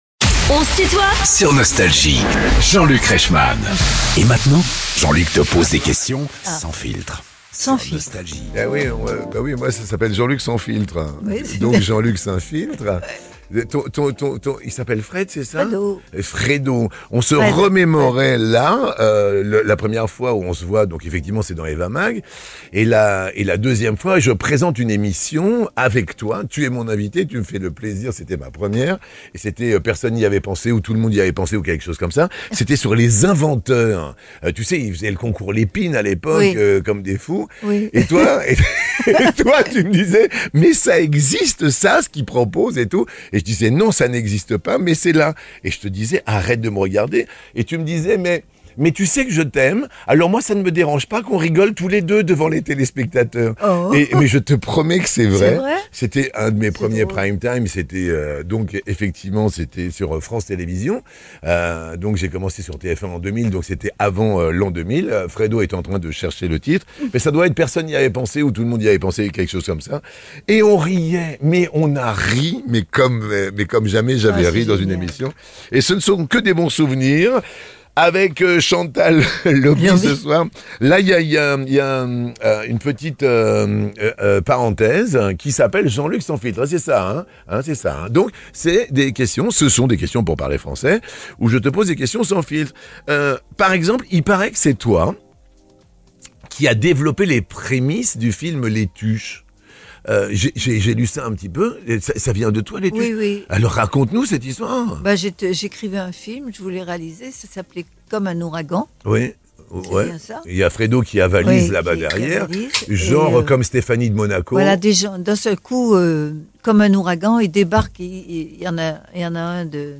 Chantal Lauby est l'invitée de "On se tutoie ?..." avec Jean-Luc Reichmann (Partie 2) ~ Les interviews Podcast